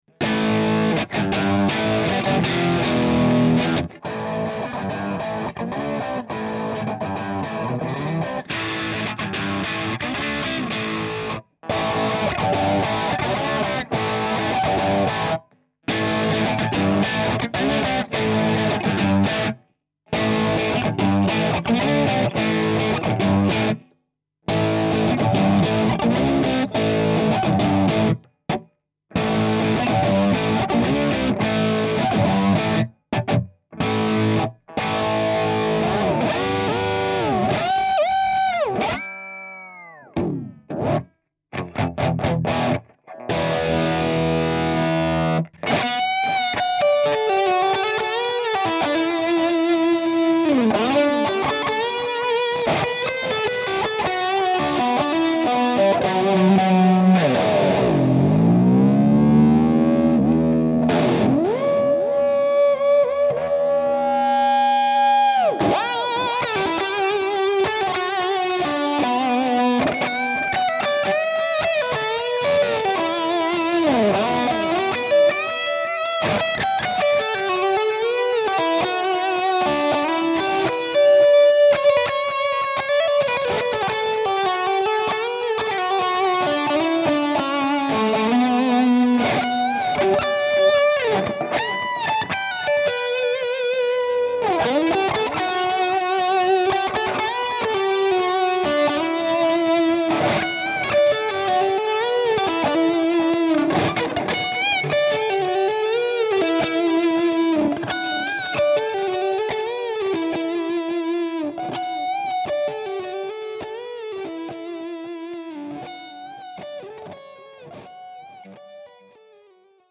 I used two mics, a SM57 in front of the Speaker (center) and a AKG C214 about 1,1 meter in front of the cab. There is no post EQ, just panned the two mics a little bit left and right. In the first seconds I cut first the SM57 and then the C214 so that you can hear how each mic sound on its own. When I repeat a phrase I switch though the PU´s. Forgive me my sloppy playing... Attachments VH4_Ibanez_Two_Mics2.mp3 VH4_Ibanez_Two_Mics2.mp3 1.8 MB · Views: 233